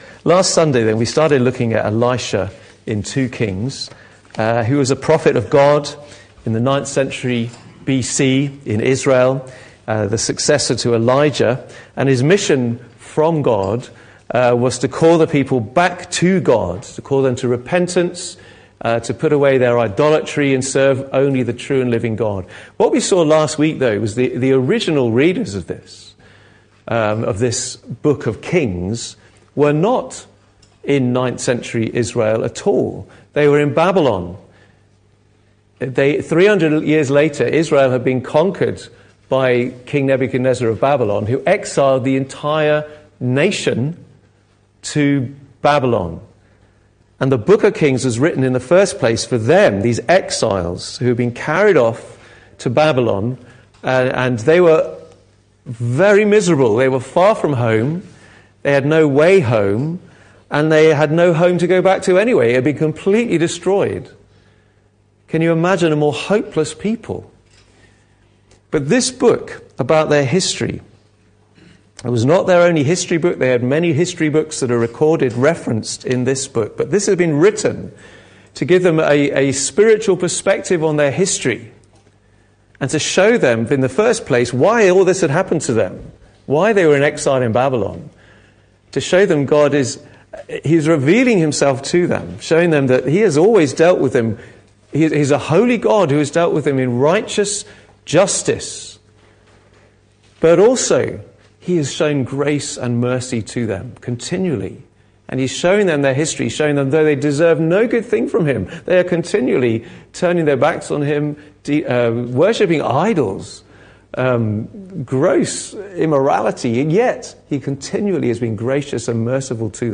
Life & Times of Elisha Passage: 2 Kings 4:1-44, 2 Kings 6:1-7 Service Type: Sunday Morning « Too Many Kings Another Day